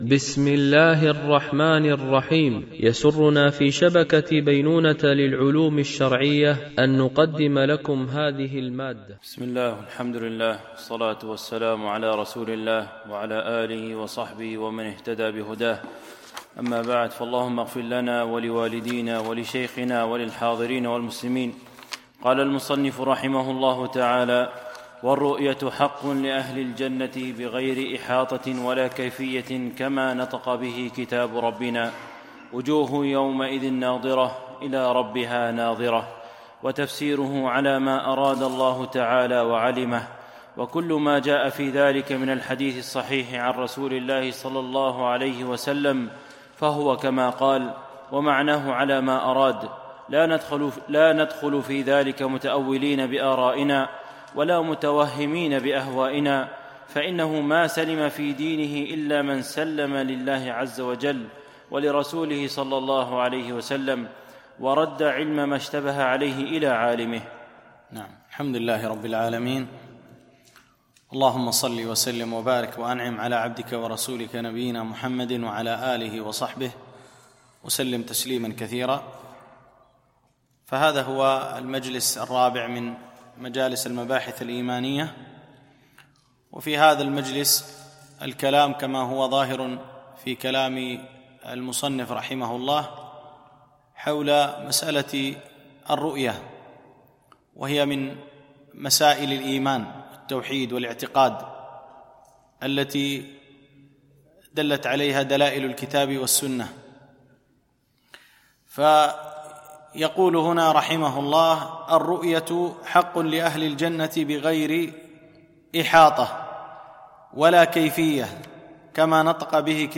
مباحث إيمانية - الدرس 4